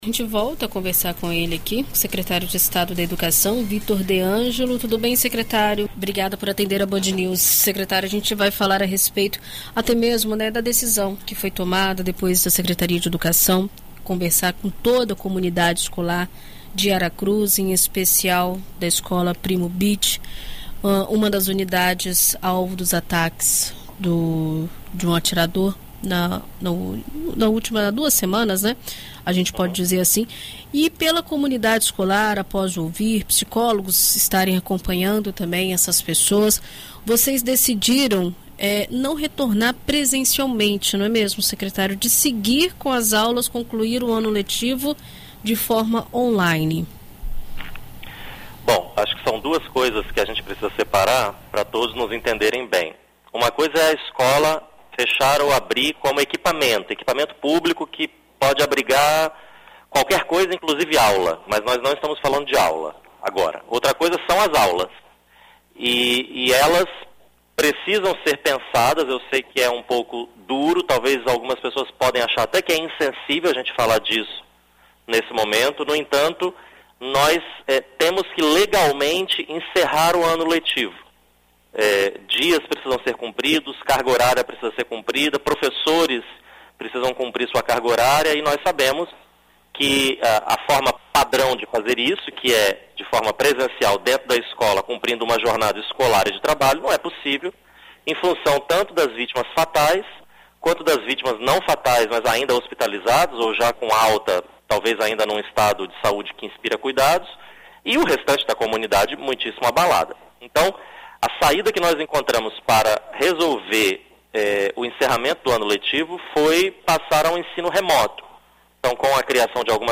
Em entrevista à BandNews FM Espírito Santo nesta quarta-feira (07), o secretário de Estado de Educação, Vitor de Ângelo, esclarece os motivos da decisão e conversa a respeito da situação da comunidade escolar na cidade duas semanas após o trágico ocorrido.